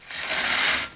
00032_Sound_deuropen.mp3